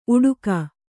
♪ uḍuka